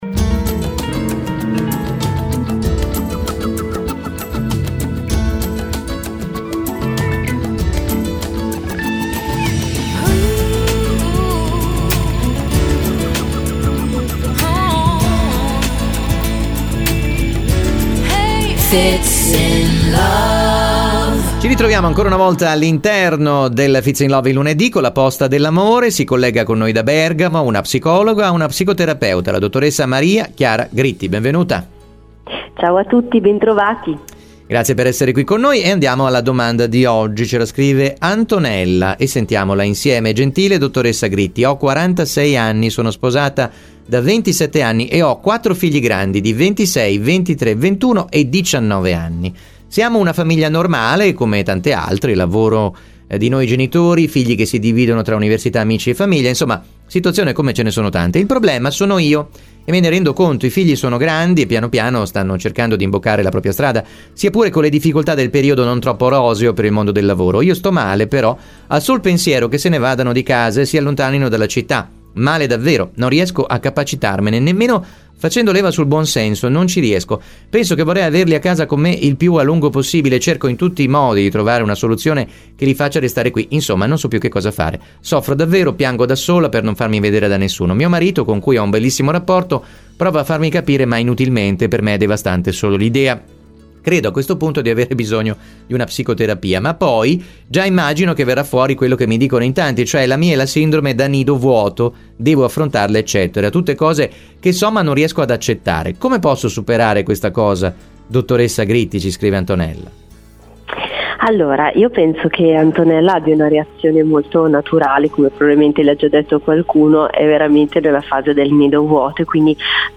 psicologa e psicoterapeuta.